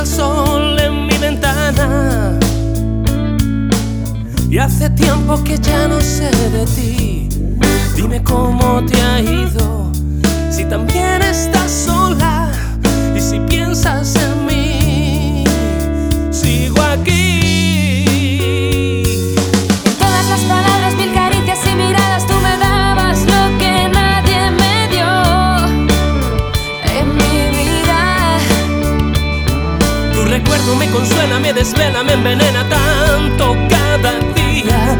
Жанр: Поп музыка
Latin, Pop